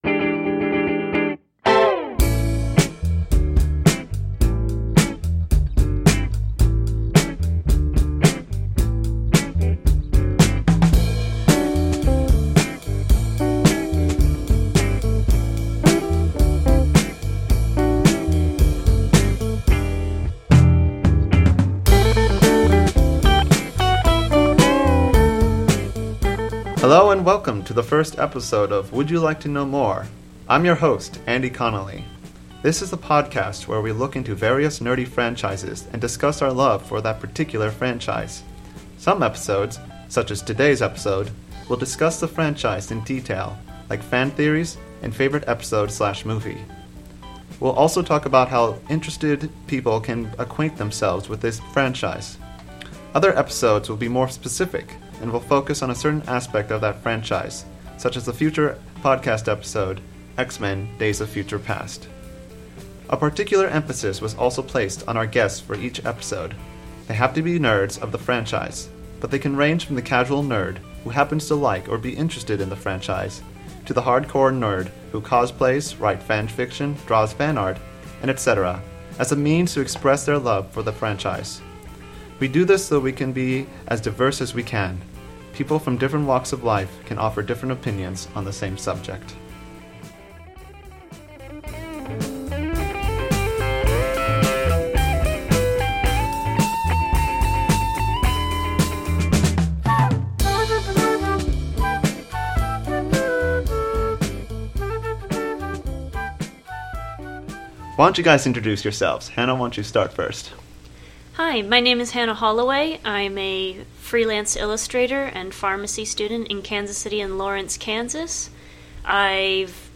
Some swearing used in this week’s episode.